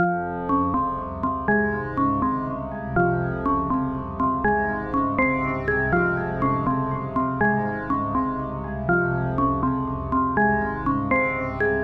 Tag: 81 bpm Trap Loops Bells Loops 1.99 MB wav Key : A